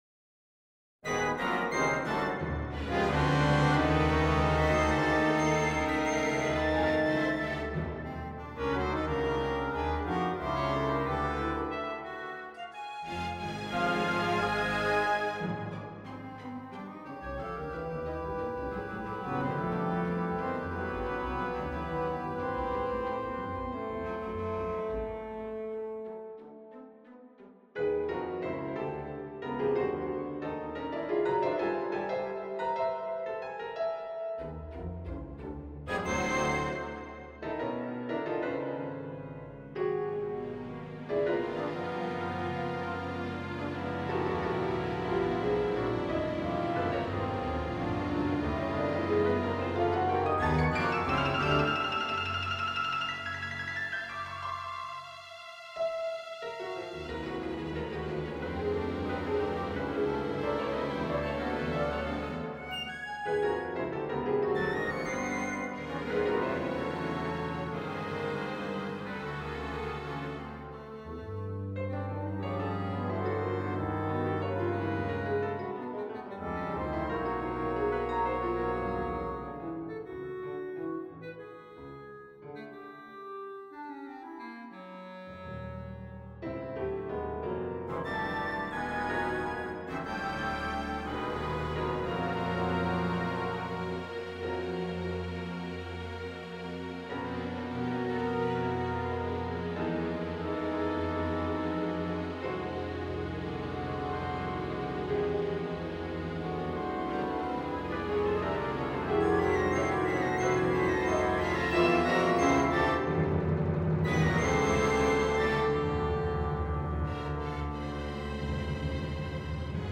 Concerto for Piano and Orchestra on a purpose-selected tone row Op.22 1. Allegro risoluto 2. Adagio molto 3. Variazioni amorfi: Allegro giocoso con anima Date Duration Download 8 February 2012 22'47" Realization (.MP3) Score (.PDF) 31.2 MB 752 KB